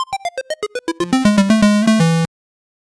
PlayNo Bingo SoundDownload
game-over.mp3